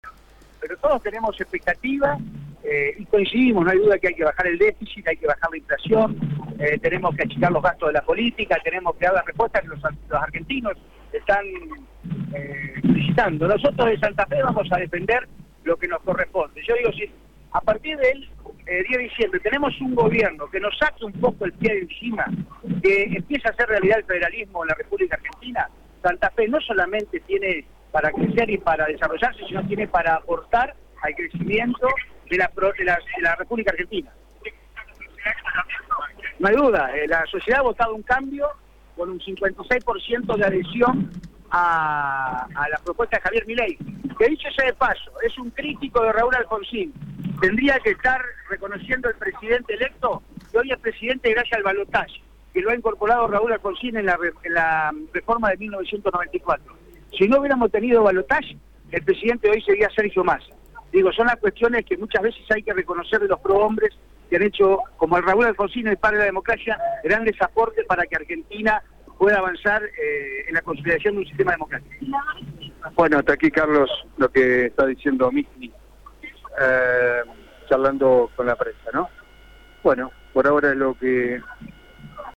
En el ingreso a la Legislatura santafesina, Radio EME está presente con su móvil para tomar contacto con los principales políticos en la asunción de Maximiliano Pullaro como Gobernador de la Provincia de Santa Fe.